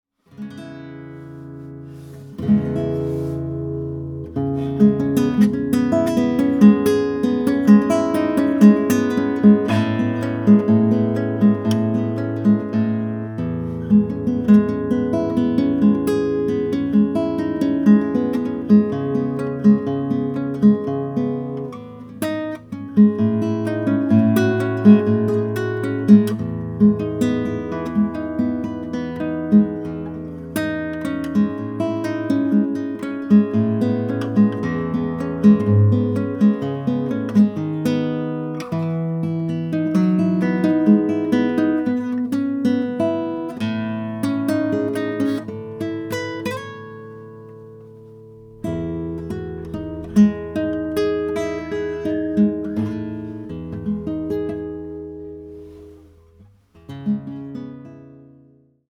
We can be a bit snobbish about electret mics but this has a rugged professional build quality, similar to the C48 era, and delivers on the sound with a very flat mid range and a little top end lift.
Sony_ECM56A_Guitar.mp3